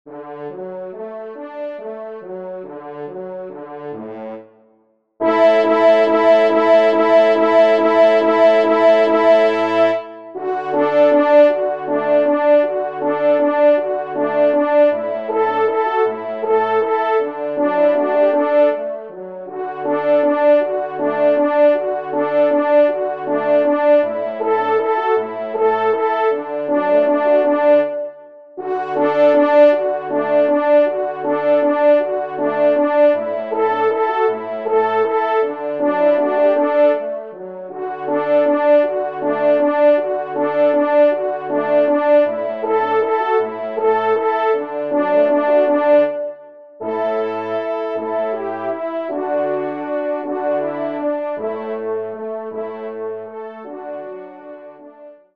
Valse
Genre :  Divertissement pour Trompes ou Cors en Ré (Valse)
2e Trompe